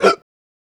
Mouth Interface (12).wav